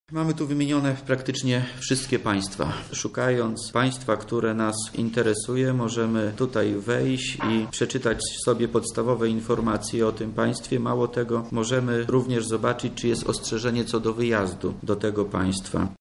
O szczegółach mówi wojewoda lubelski Wojciech Wilk